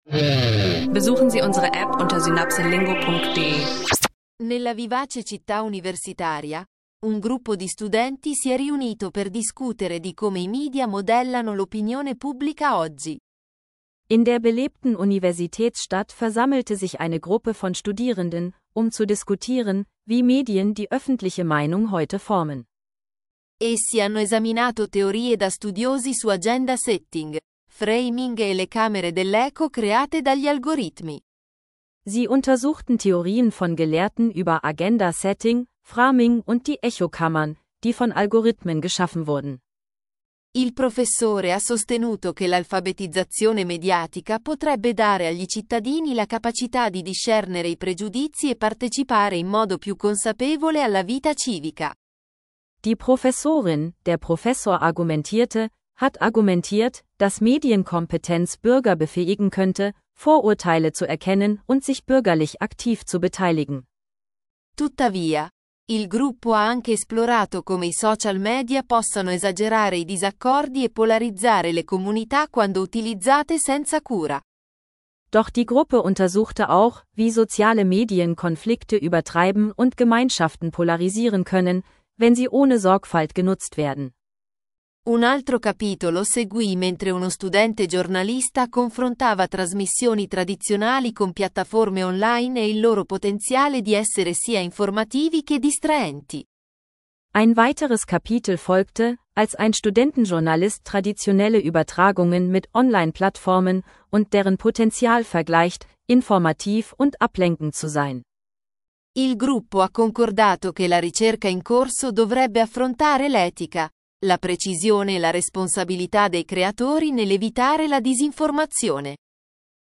In dieser Folge diskutieren Studierende, wie Medien Gesellschaft beeinflussen, welche Rolle Algorithmen spielen und wie man Medienkompetenz im Alltag stärkt – perfekt zum Italienisch lernen mit Podcast.